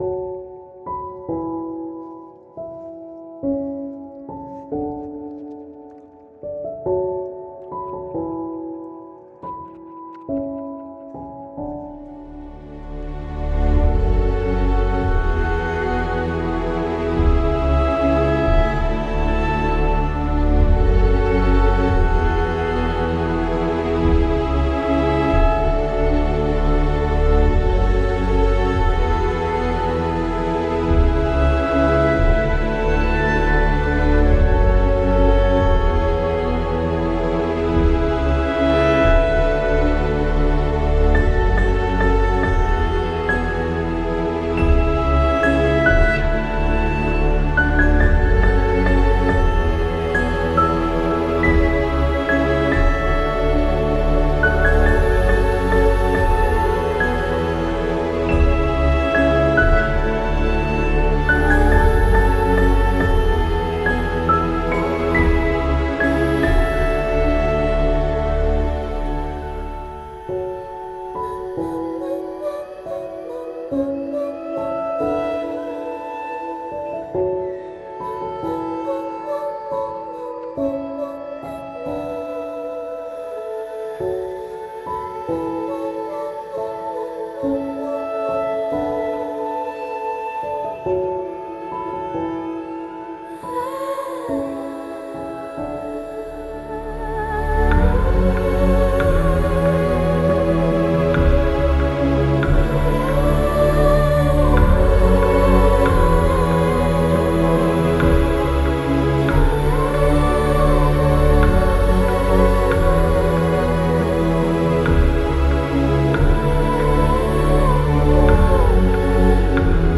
موزیک بی کلام امبینت